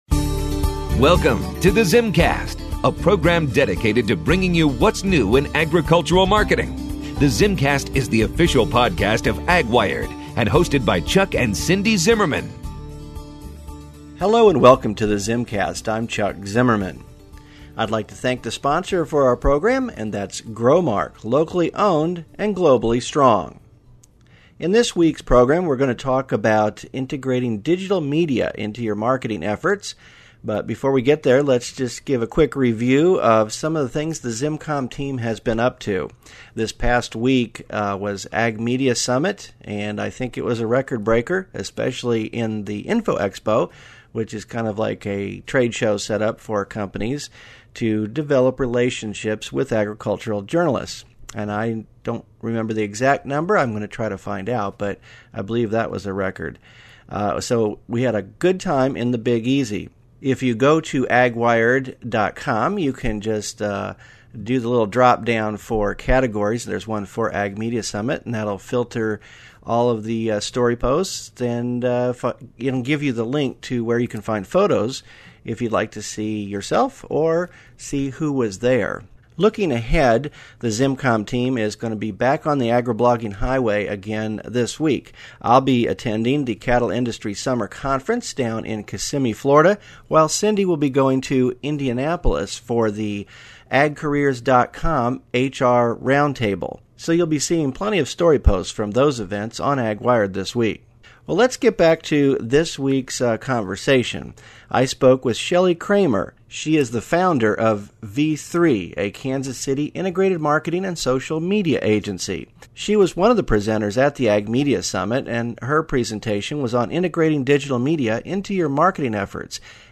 The ZimmCast is the official weekly podcast of AgWired.